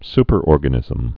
(spər-ôrgə-nĭzəm)